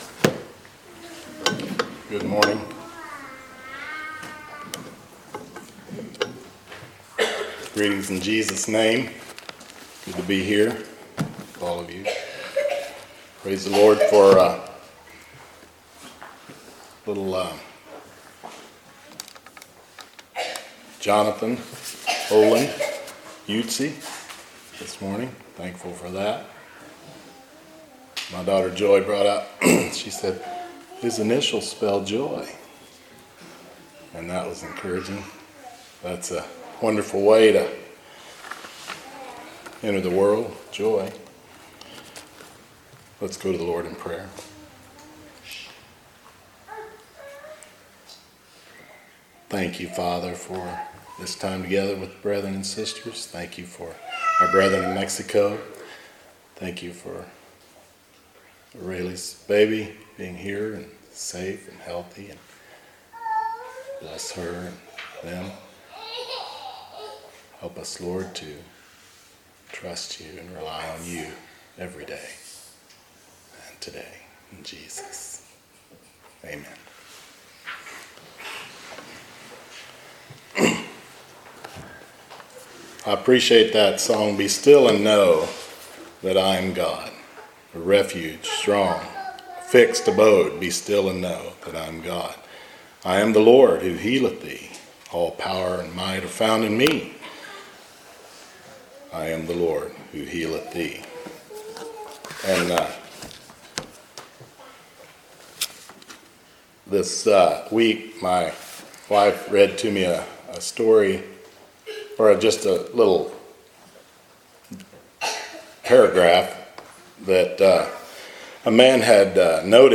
Church Of Monett Sermon Archive